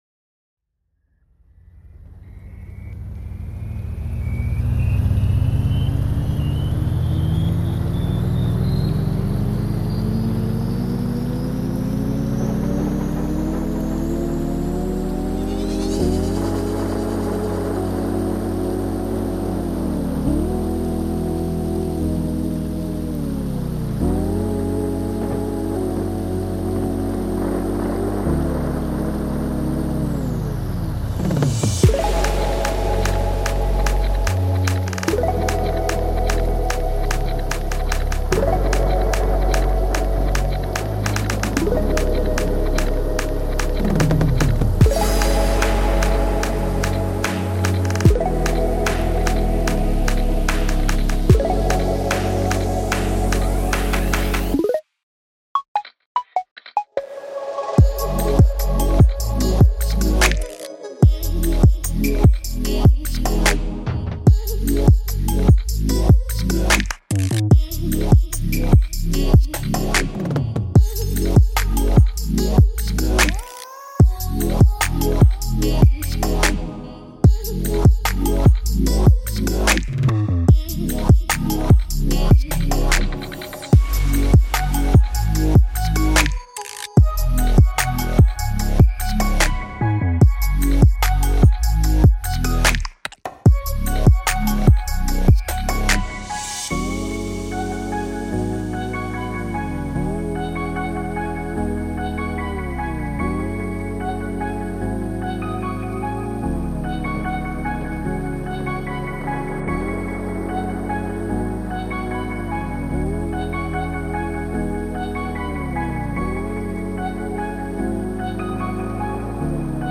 атмосферная инструментальная композиция